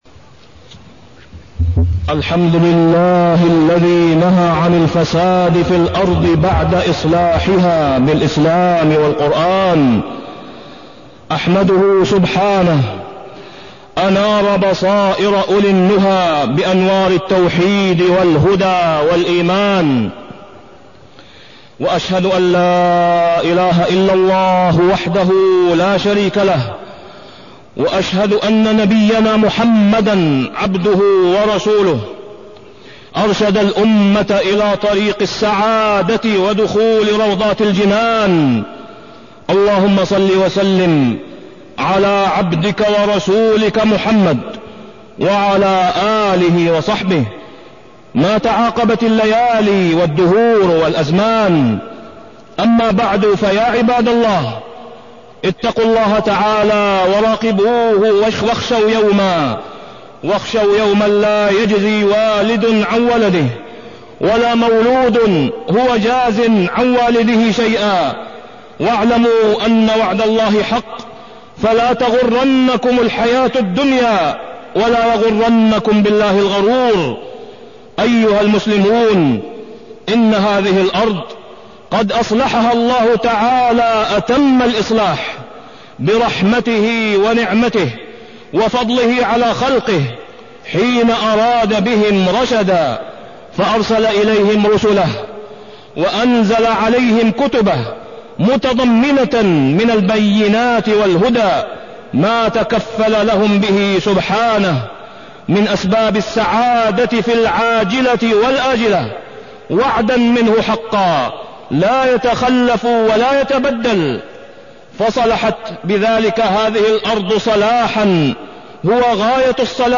تاريخ النشر ٢٠ جمادى الأولى ١٤٢٢ هـ المكان: المسجد الحرام الشيخ: فضيلة الشيخ د. أسامة بن عبدالله خياط فضيلة الشيخ د. أسامة بن عبدالله خياط صور الإفساد في الأرض